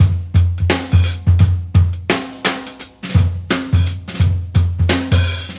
• 86 Bpm '00s Jazz Drum Beat F# Key.wav
Free drum beat - kick tuned to the F# note. Loudest frequency: 1869Hz
86-bpm-00s-jazz-drum-beat-f-sharp-key-ZAp.wav